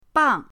bang4.mp3